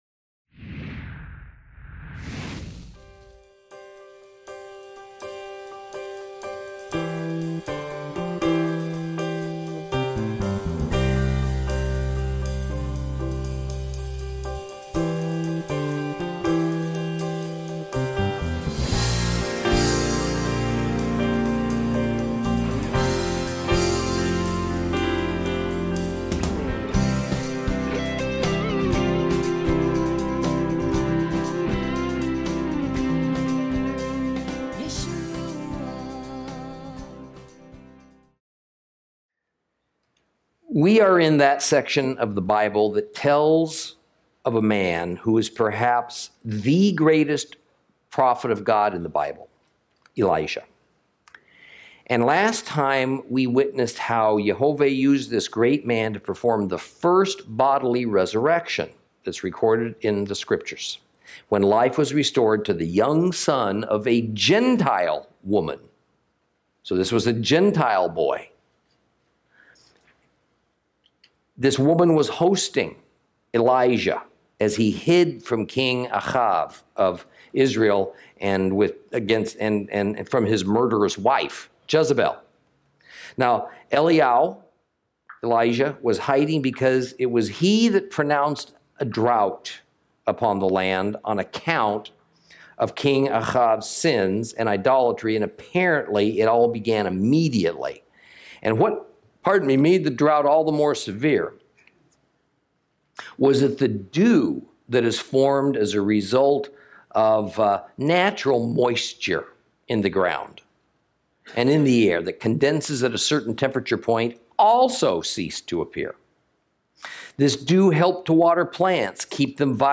Lesson 29 Ch18 - Torah Class